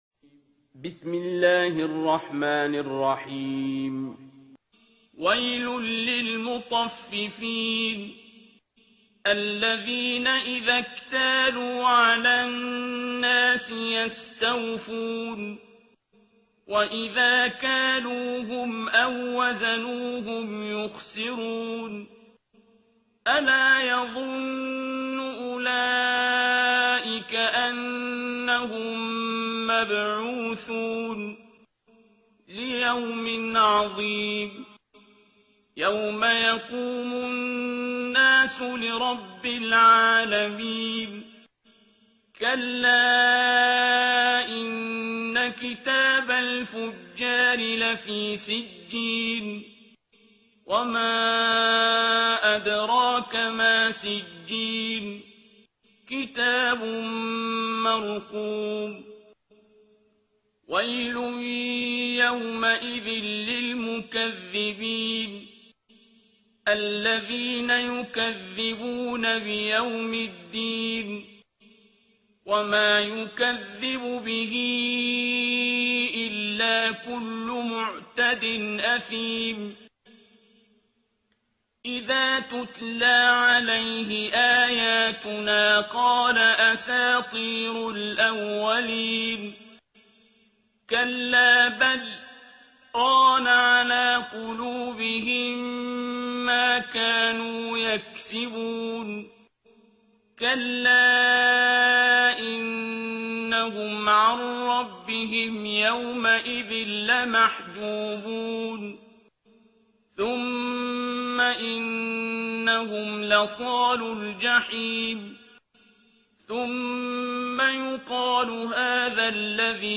ترتیل سوره مطففین با صدای عبدالباسط عبدالصمد
083-Abdul-Basit-Surah-Al-Mutaffifin.mp3